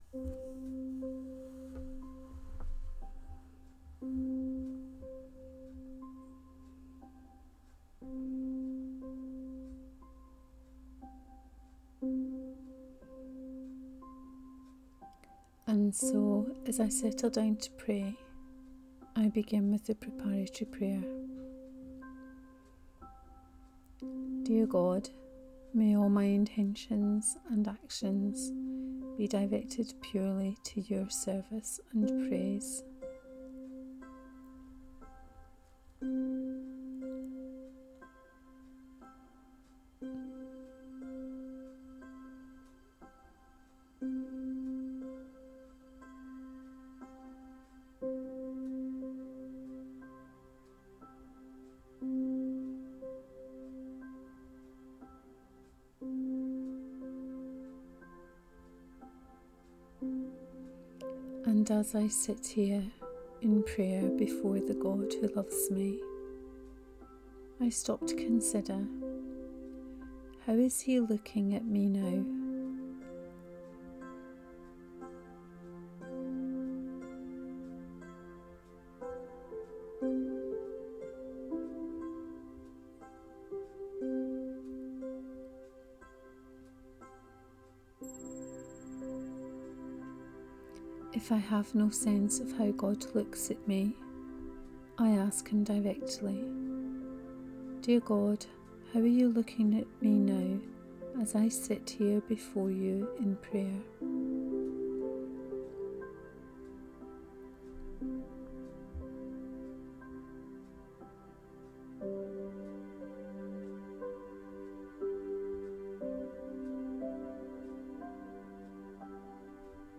Guided prayer from the Sunday lectionary.